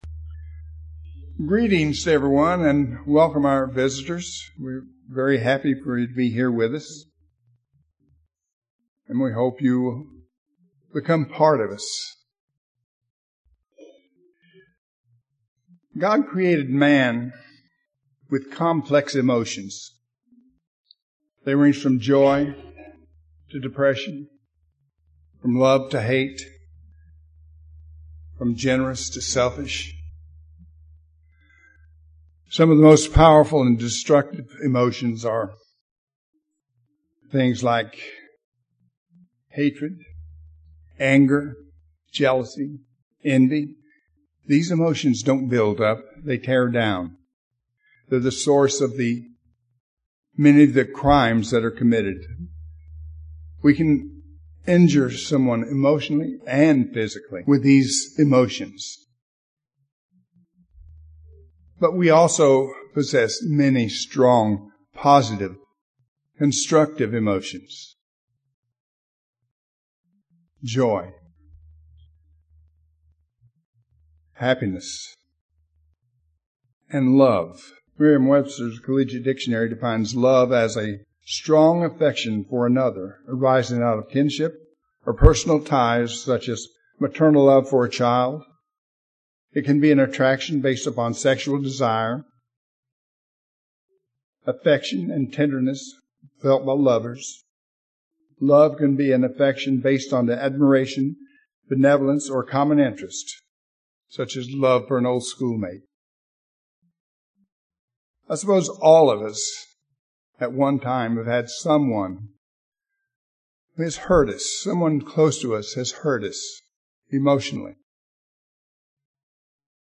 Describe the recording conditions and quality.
Given in Jonesboro, AR